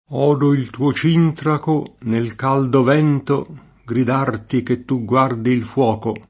cintraco [©&ntrako] s. m. (stor.); pl. -chi — es. con acc. scr.: odo il tuo Cìntraco, nel caldo Vento, gridarti che tu guardi il fuoco [
0do il tuo ©&ntrako, nel k#ldo v$nto, grid#rti ke ttu ggU#rdi il fU0ko] (D’Annunzio)